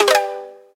correct.ogg